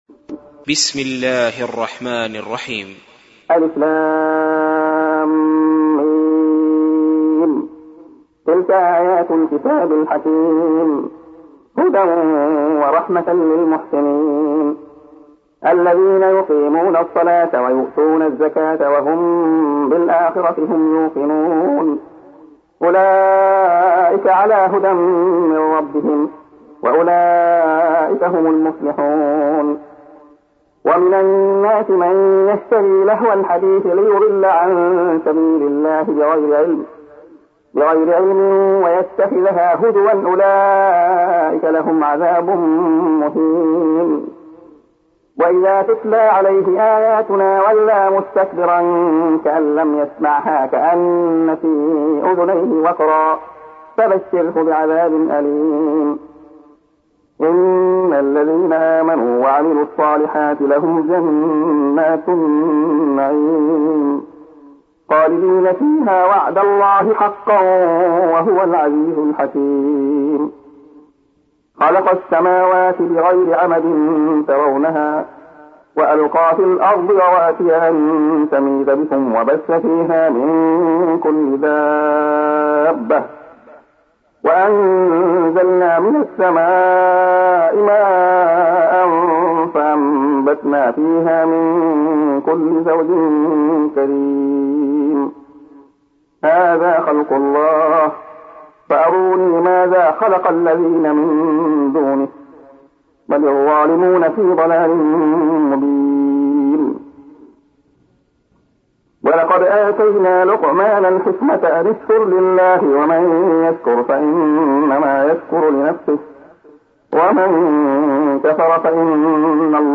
سُورَةُ لُقۡمَانَ بصوت الشيخ عبدالله الخياط